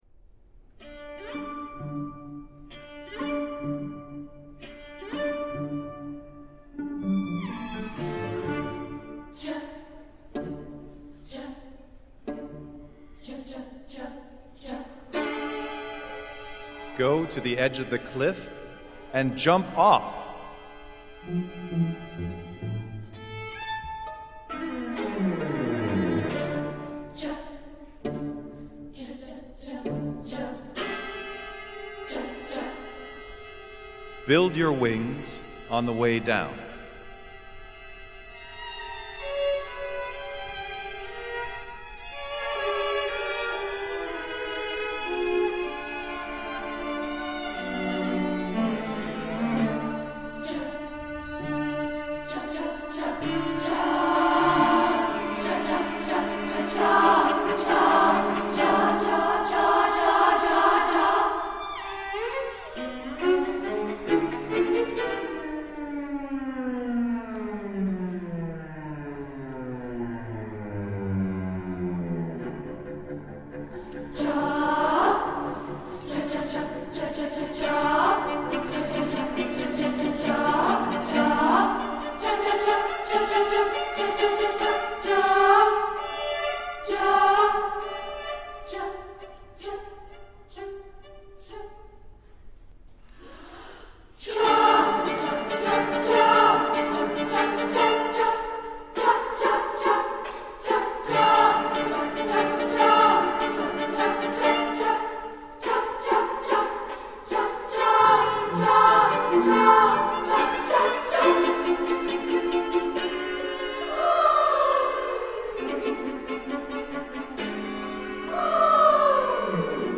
A sonic saga falling and flying.
SSA, narrator, string quartet OR SSA, piano